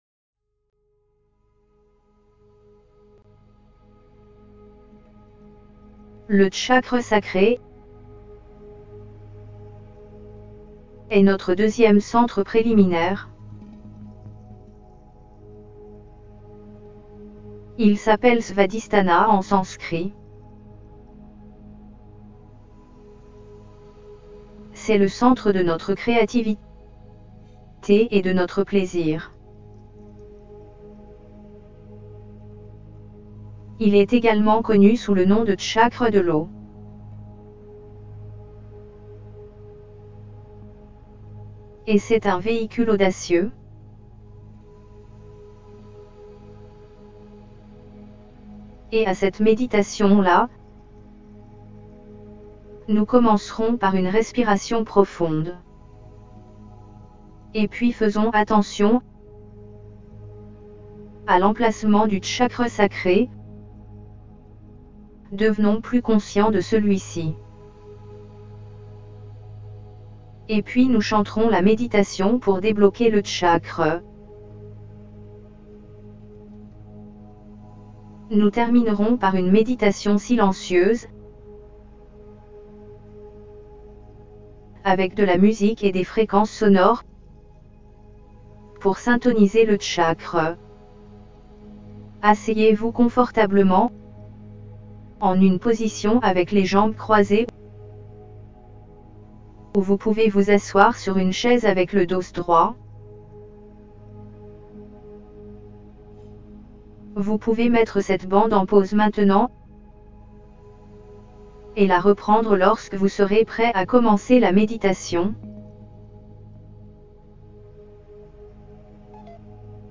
2SacralChakraHealingGuidedMeditationFR.mp3